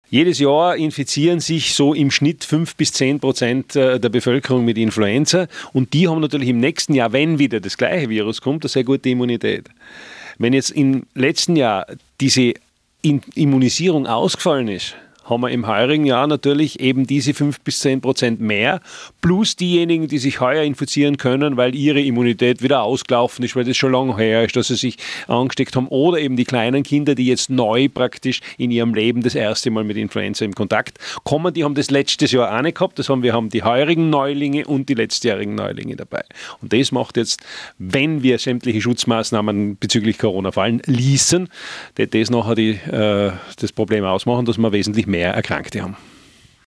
Life Radio Interview